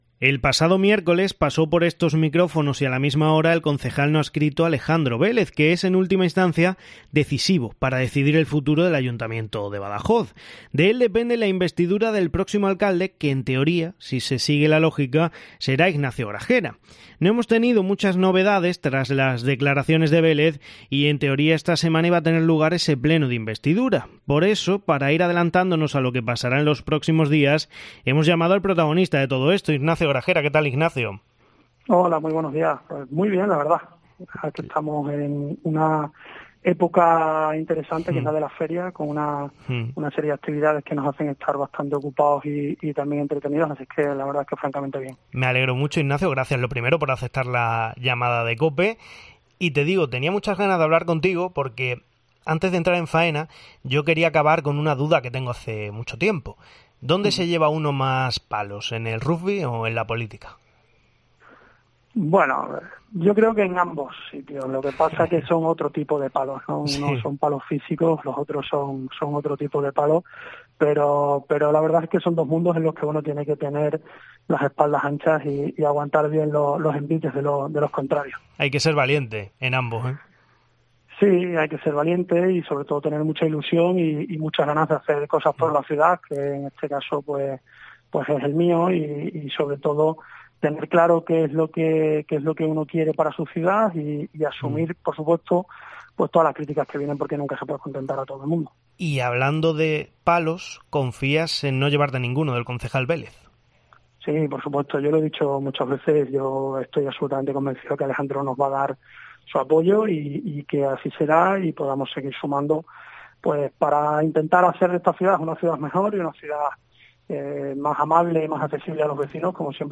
El actual alcalde en funciones, que ha sido entrevistado en Herrera en Cope Badajoz, es optimista y afirma que las negociaciones con Vélez van por buen camino